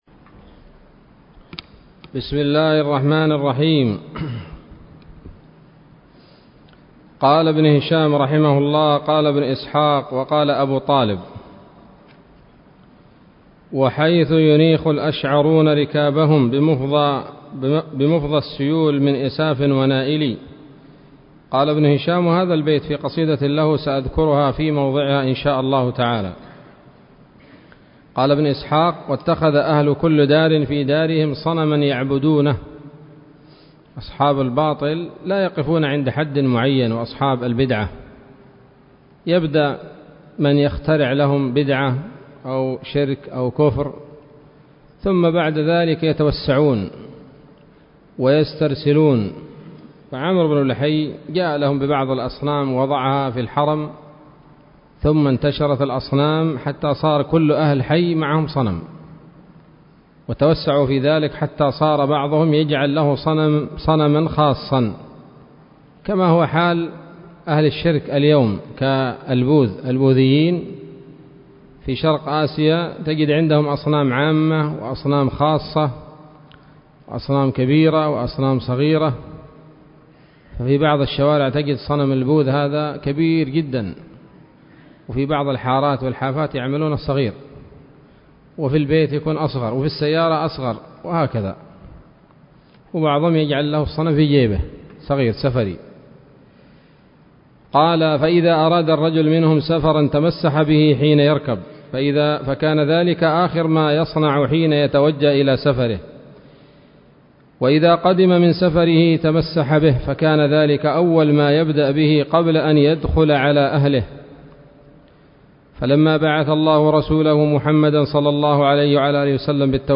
الدرس الثامن من التعليق على كتاب السيرة النبوية لابن هشام